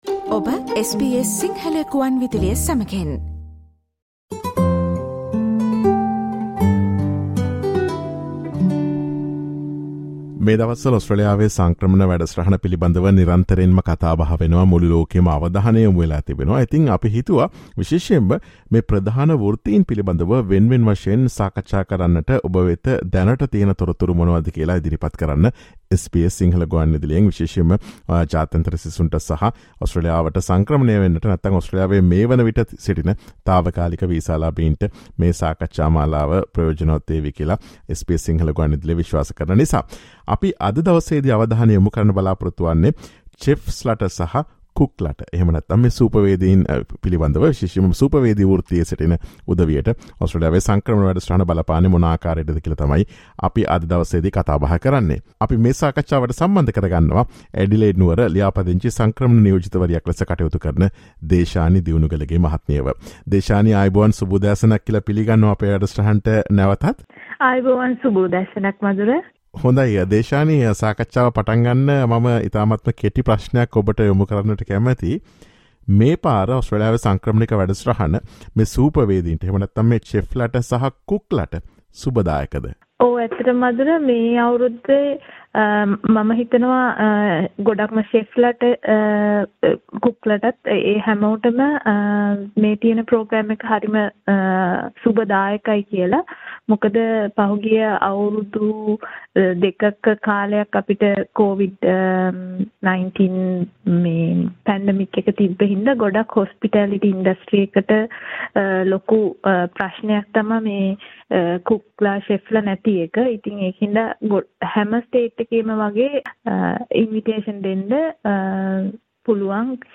Listen to SBS Sinhala Radio's discussion on pathways for Chefs and Cooks to gain permanent residency in Australia.